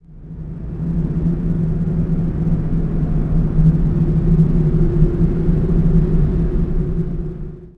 WIND1LO.WAV